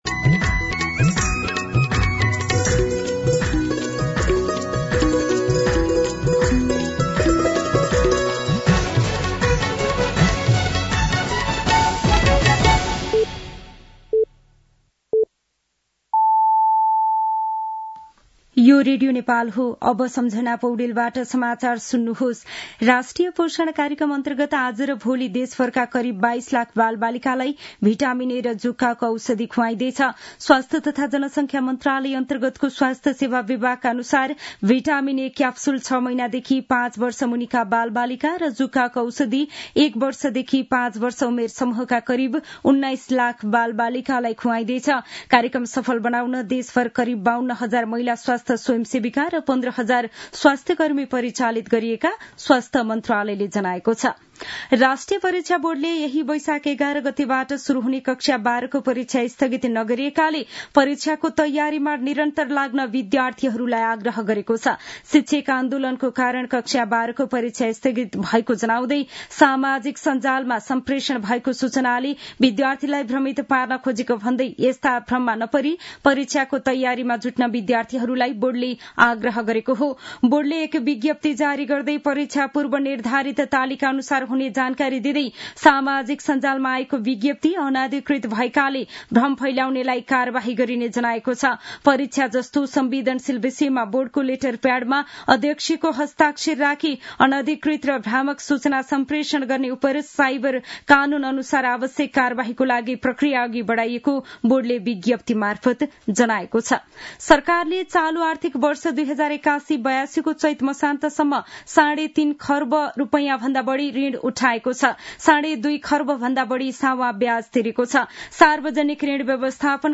दिउँसो ४ बजेको नेपाली समाचार : ६ वैशाख , २०८२
4pm-Nepali-News-2.mp3